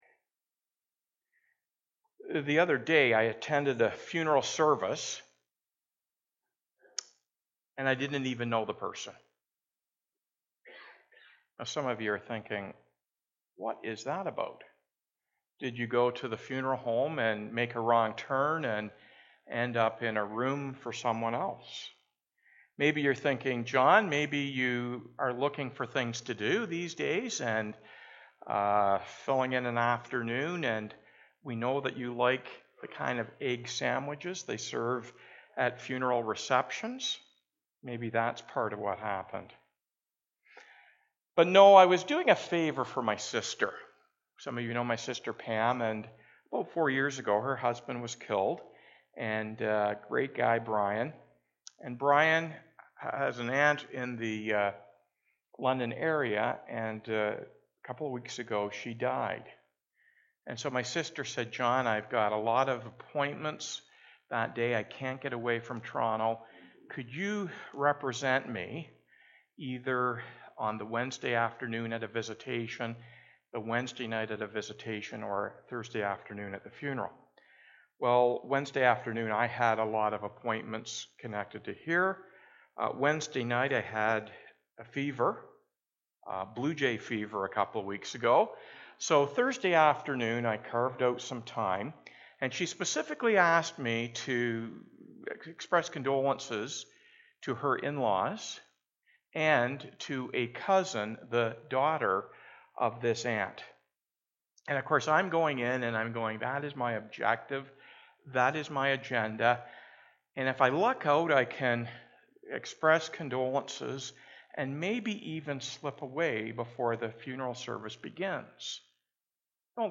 16-34 Please excuse the annoying tone that appears randomly starting around the 40 minute mark.